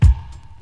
MB Kick (7).wav